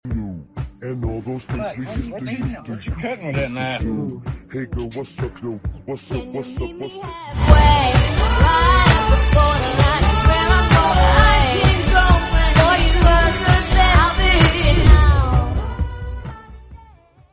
late night post with bad quality, bad transitions and it’s choppy but who cares